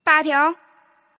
Index of /client/common_mahjong_tianjin/mahjongjinghai/update/1129/res/sfx/tianjin/woman/